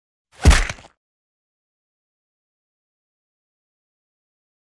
赤手空拳击中肉体－高频2-YS070524.mp3
通用动作/01人物/03武术动作类/空拳打斗/赤手空拳击中肉体－高频2-YS070524.mp3